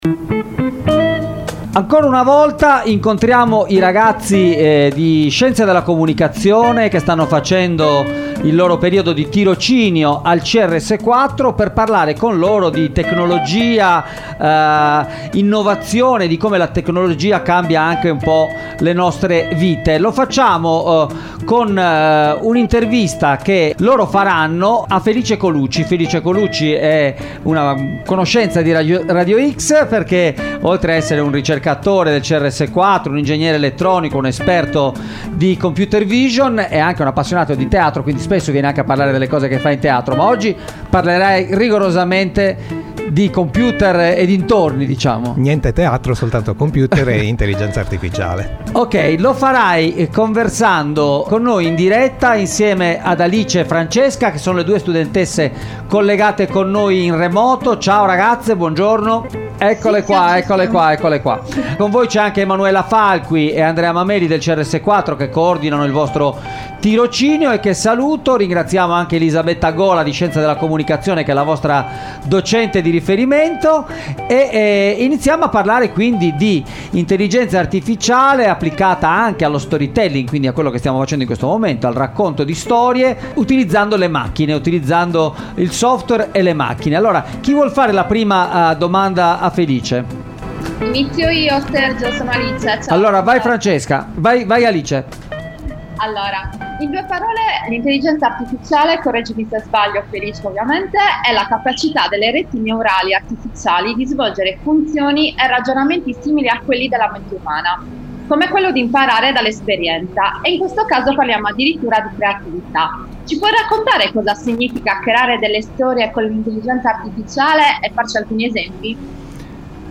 Etica e Intelligenza Artificiale: se le macchine imparano a scrivere un racconto - intervista